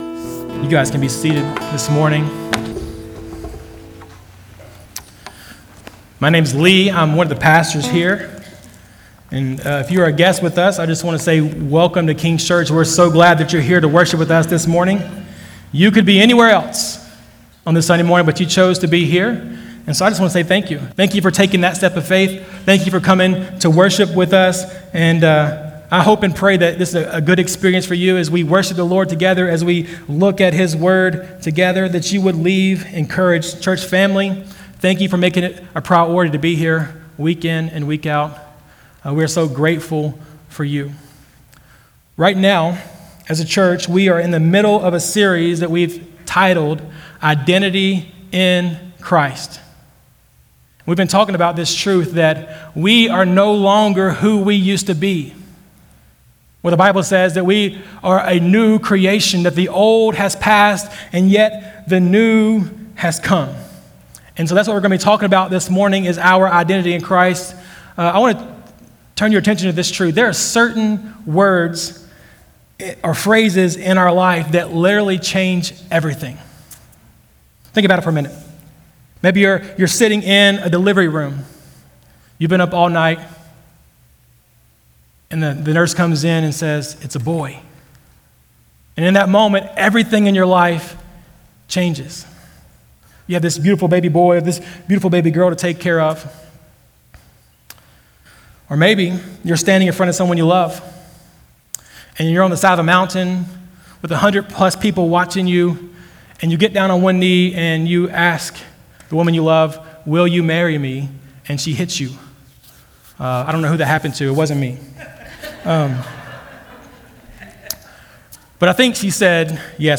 November30sermon.mp3